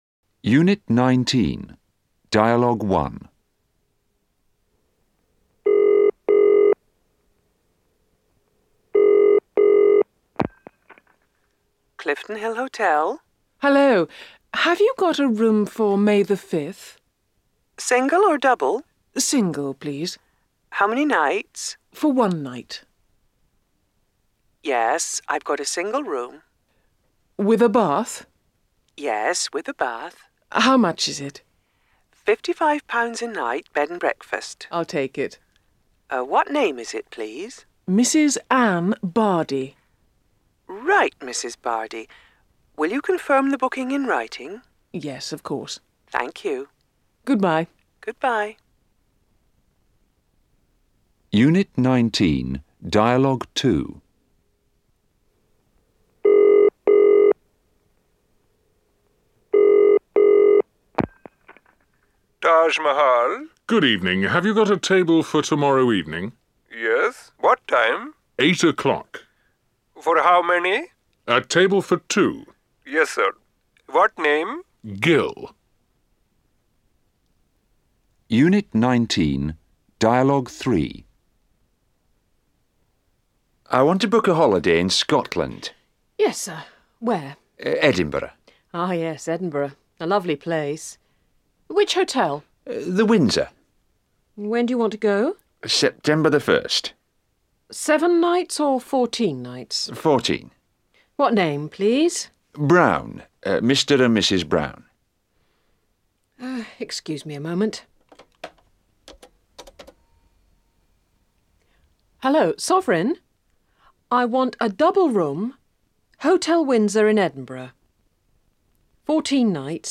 17 - Unit 19, Dialogues.mp3